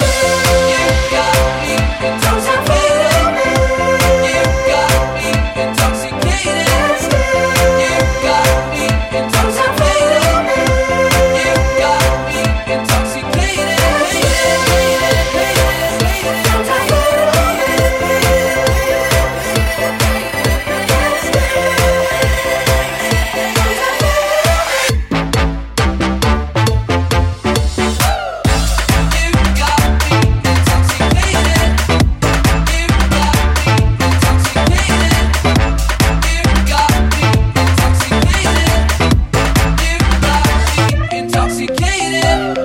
Genere: deep, dance, edm, club, remix